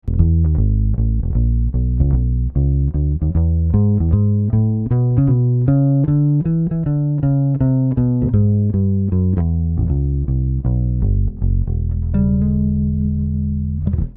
Rickenbacker Walking Bass
A Rickenbacker straight into the DI02.
DI02 - Rickenbacker Walking Bass - BassTheWorld.mp3